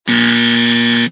Buzzer Sound Button: Meme Soundboard Unblocked
Buzzer